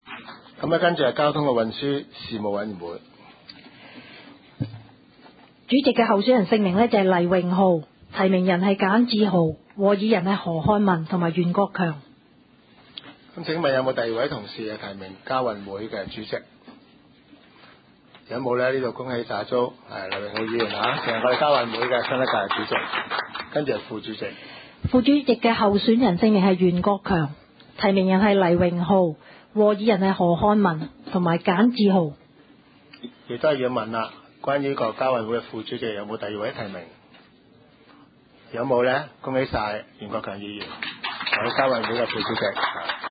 委员会会议的录音记录
交通及运输事务委员会第一次会议 日期: 2012-01-17 (星期二) 时间: 下午2时30分 地点: 九龙黄大仙龙翔道138号 龙翔办公大楼6楼 黄大仙区议会会议室 议程 讨论时间 I 选举委员会主席及副主席 0:00:43 全部展开 全部收回 议程:I 选举委员会主席及副主席 讨论时间: 0:00:43 前一页 返回页首 如欲参阅以上文件所载档案较大的附件或受版权保护的附件，请向 区议会秘书处 或有关版权持有人（按情况）查询。